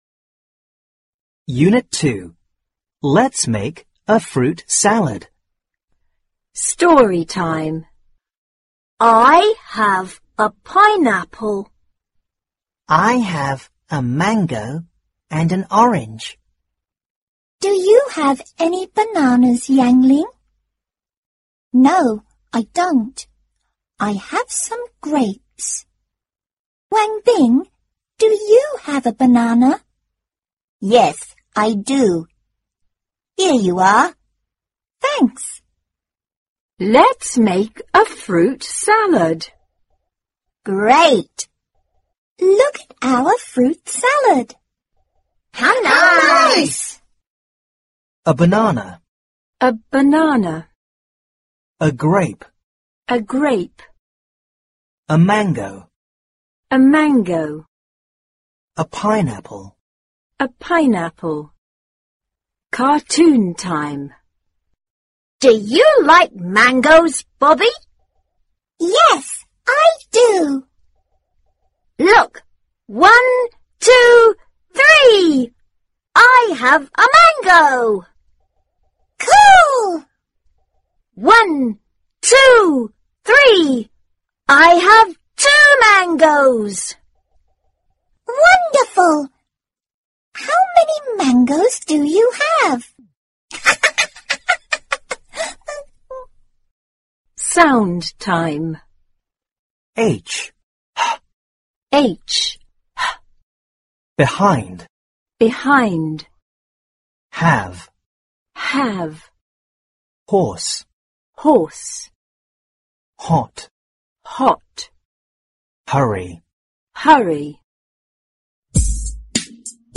四年级英语上Unit 2 课文.mp3